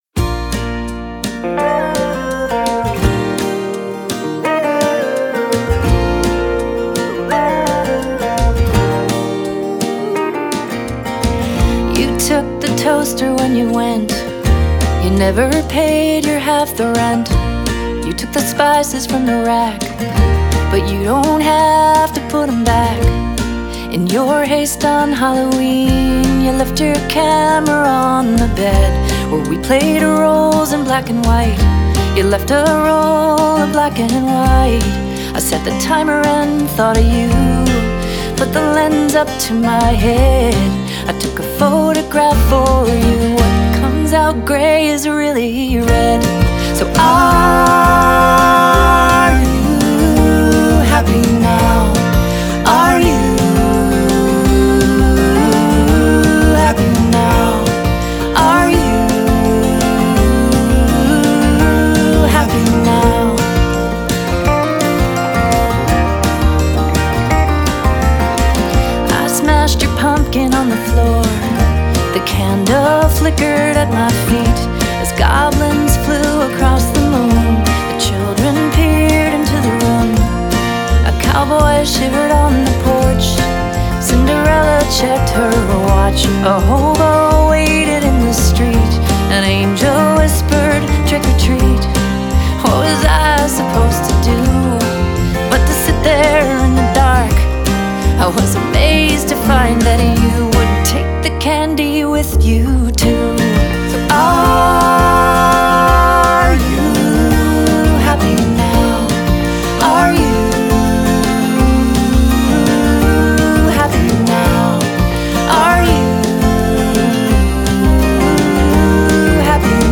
who was nice enough to contribute harmonies.